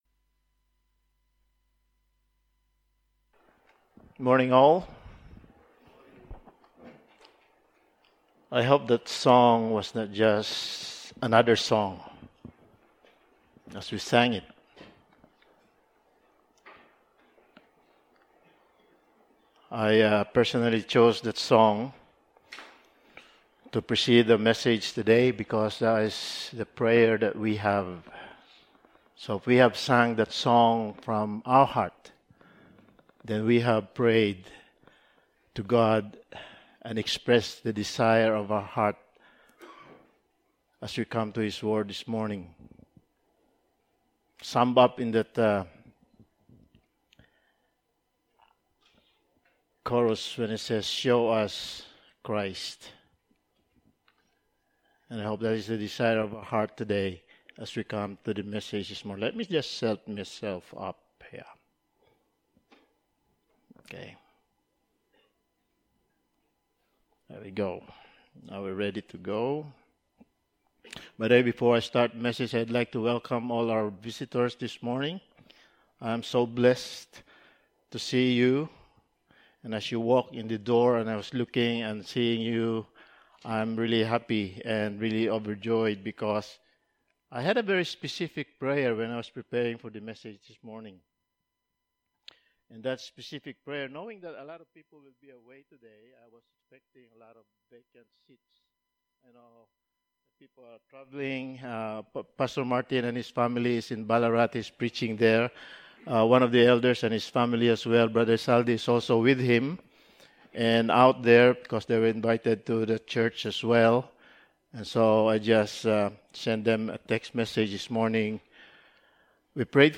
Passage: John 11:25 Service Type: Sunday Morning